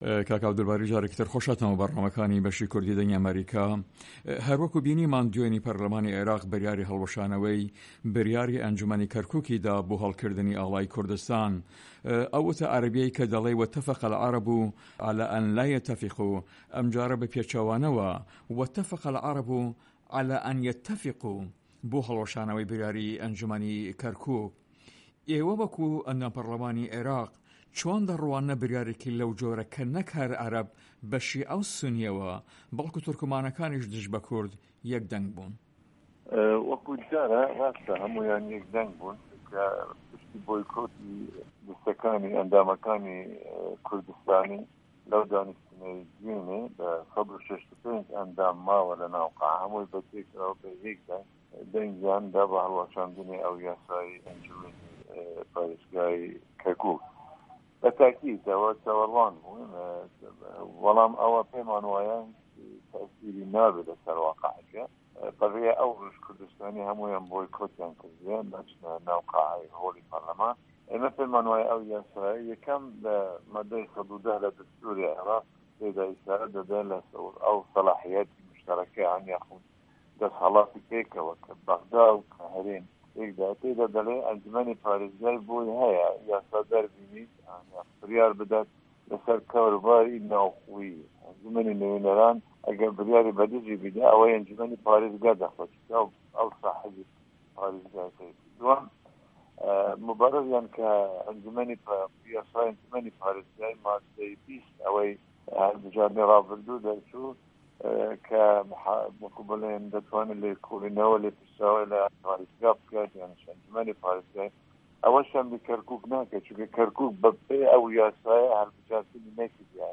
Interview with Abdulbari Zibari